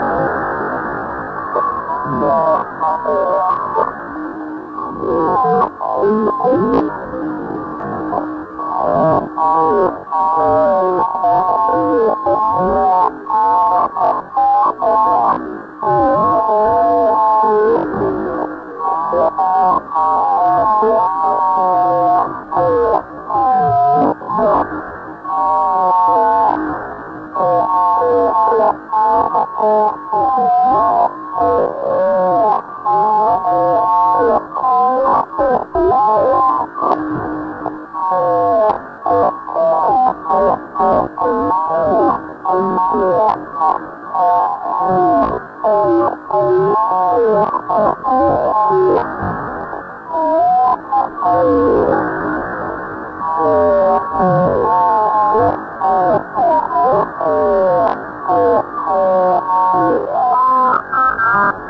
描述：从基站到外星飞船的信息。
Tag: 外来 aliencommunication alienspeech 消息 广播